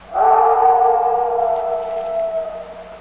Et le fameux hurlement.
Hurlement.wav